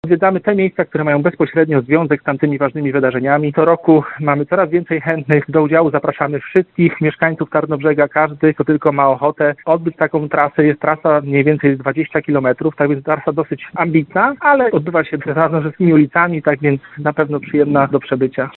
Mówi współorganizator inicjatywy, tarnobrzeski radny, szef struktur miejskich PiS, Kamil Kalinka.